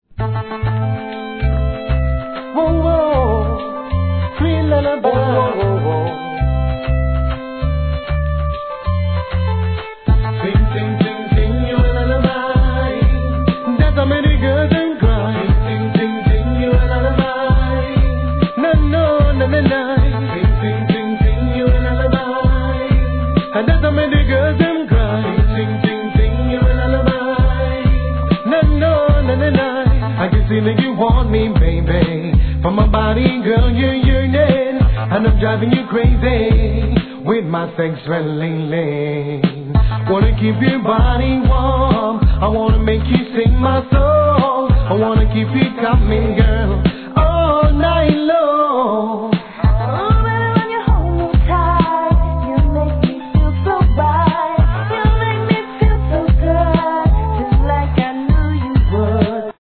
REGGAE
フィメール・ヴォーカルとのコンビネーション!